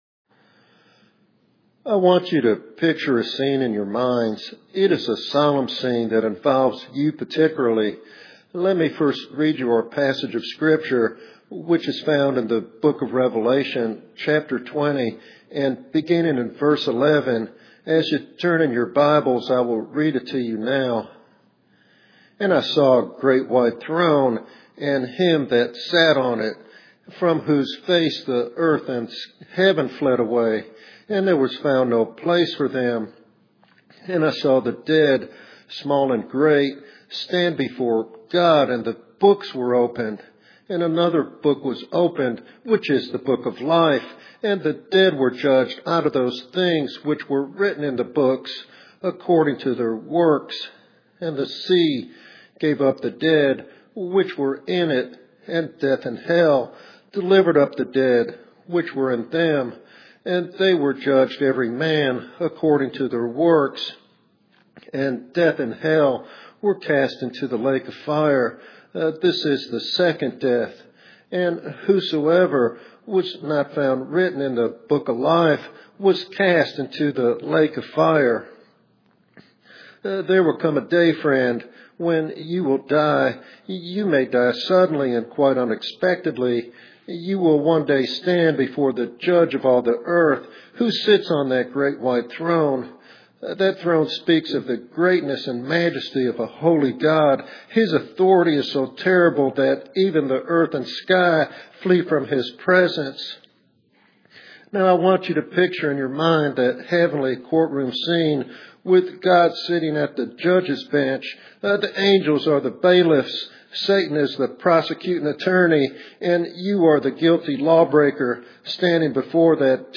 This evangelistic sermon emphasizes the urgency of salvation in light of sudden death and the hope found only in Christ's atoning blood.